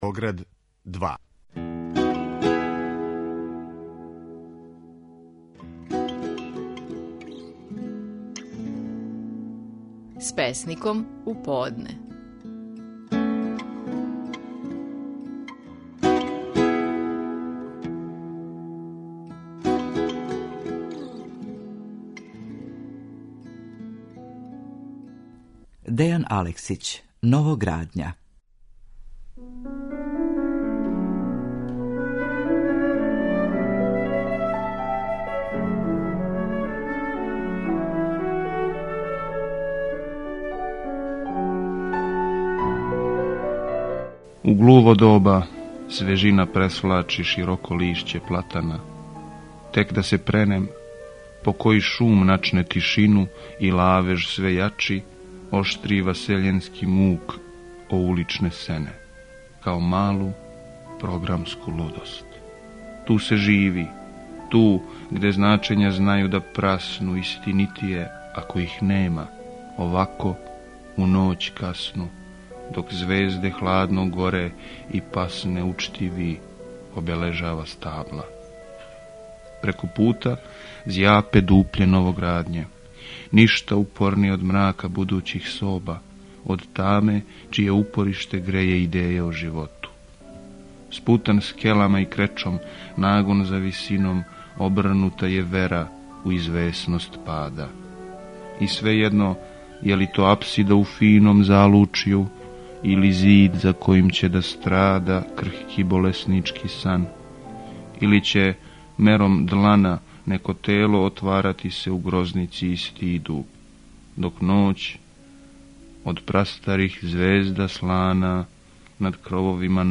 Стихови наших најпознатијих песника, у интерпретацији аутора